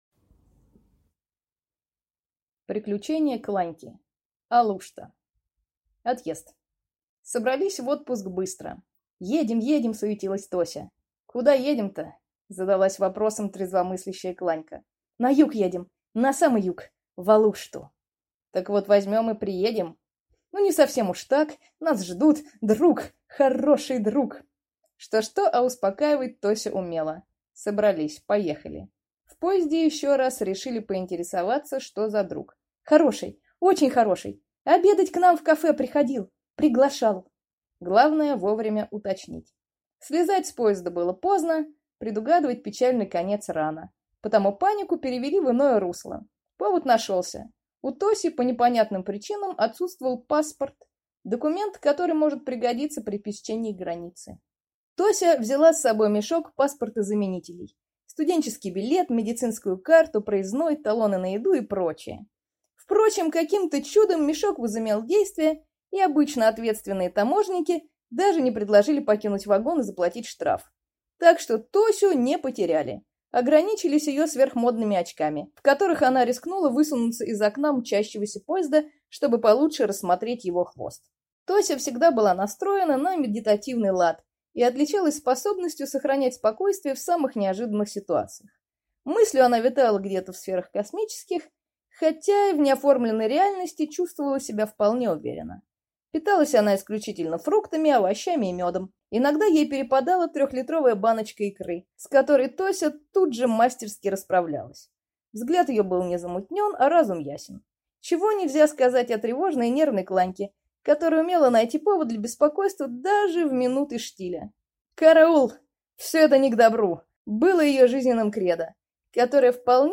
Аудиокнига 1.
Прослушать и бесплатно скачать фрагмент аудиокниги